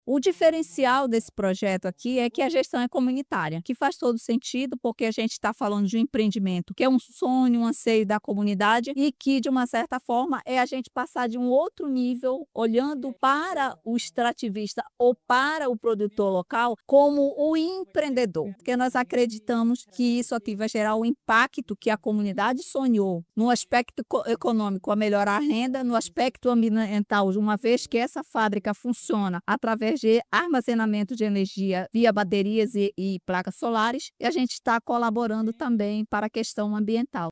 SONORAMULHER-FAS-.mp3